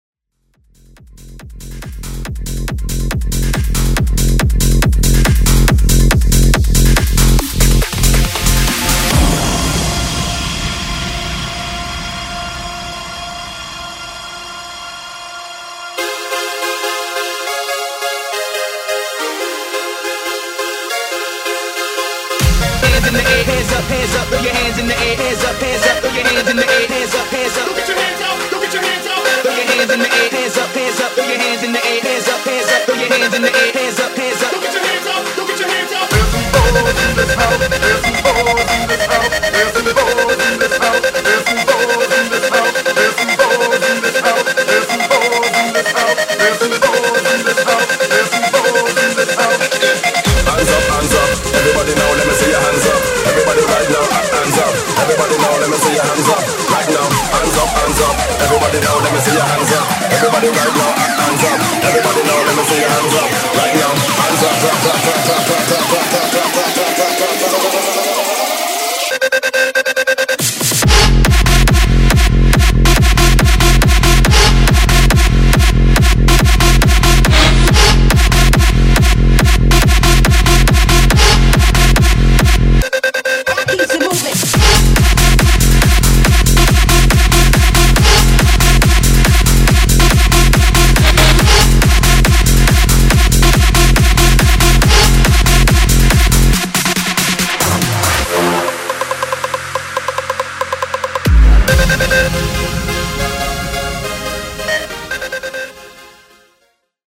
EDM , MASHUPS , TOP40 Version: Clean BPM: 128 Time